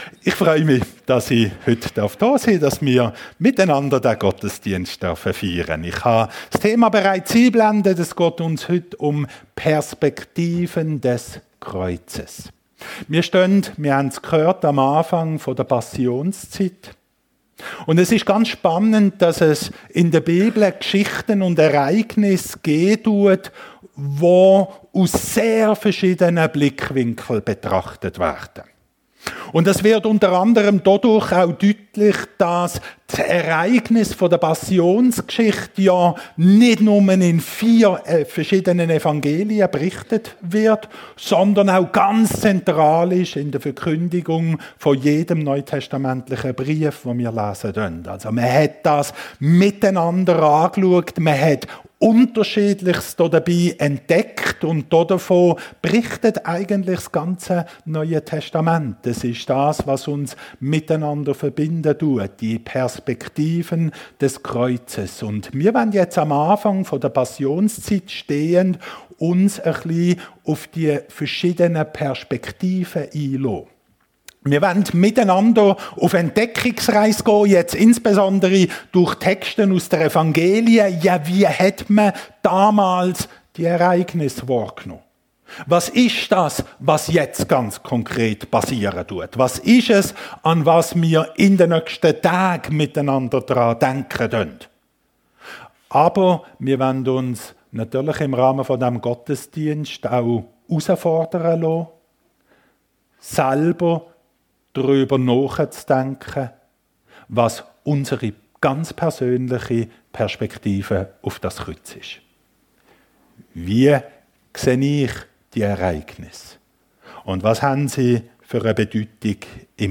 Perspektiven des Kreuzes ~ FEG Sumiswald - Predigten Podcast